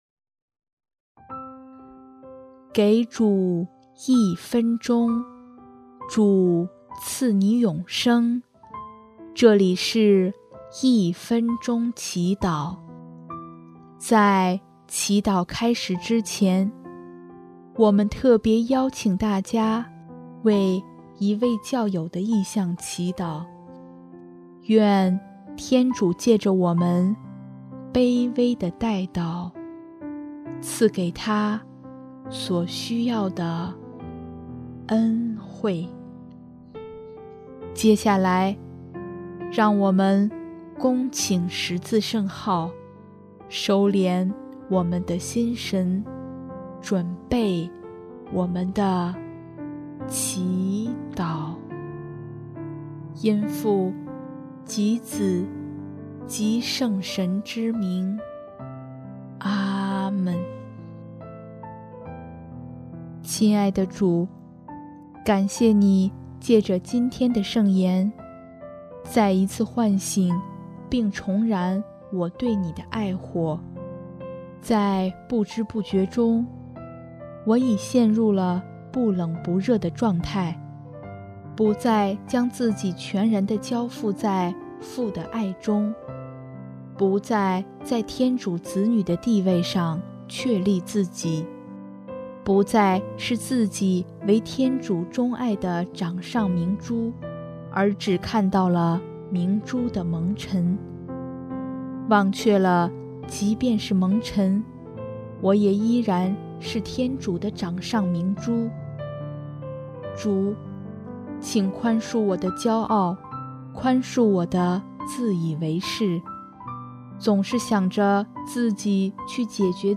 音乐： 第一届华语圣歌大赛参赛歌曲《圣神的话语》（core：感谢耶稣赐予生命，也求主祝福自己，在自己想走的路上顺利的走完）